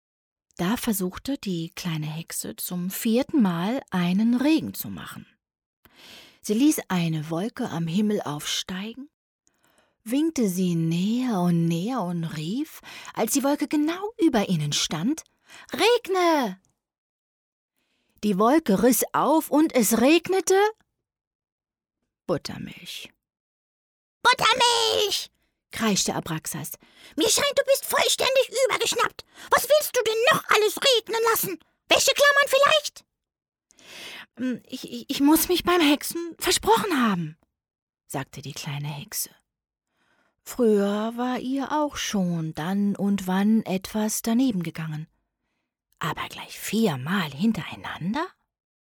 hell, fein, zart, sehr variabel
Audiobook (Hörbuch)